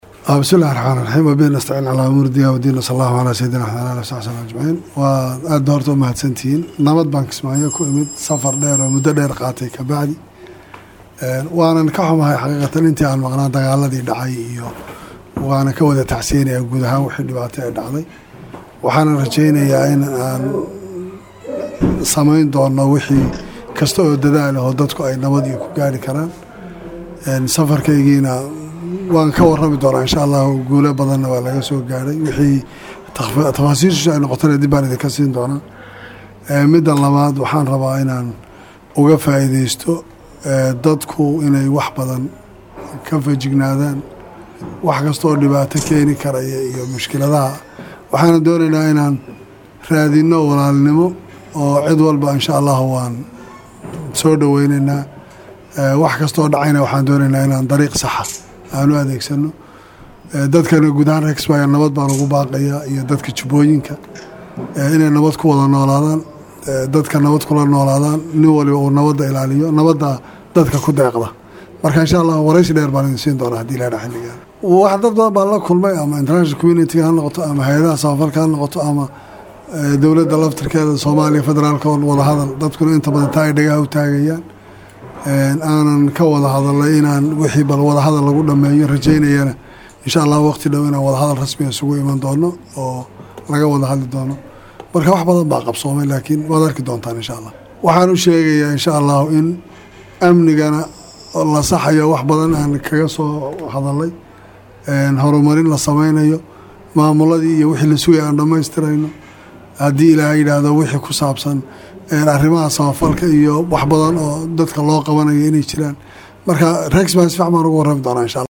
waxa uu si toos ah ugu gudbay xarunta madaxtooyada , asagoo wareysi kooban siiyay saxafada.